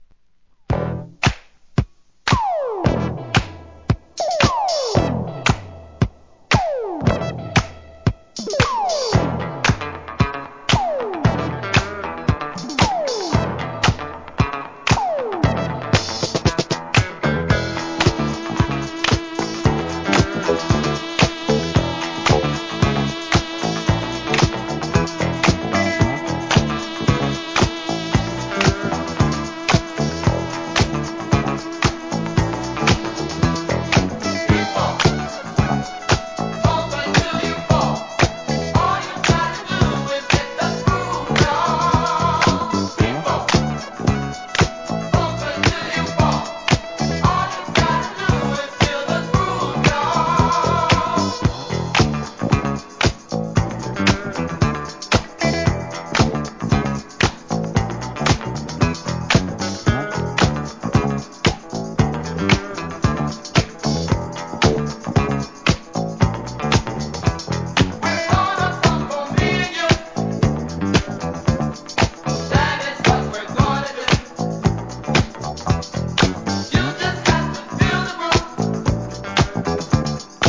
SOUL/FUNK/etc... 店舗 数量 カートに入れる お気に入りに追加 1981年の人気マイナーDISCO!!!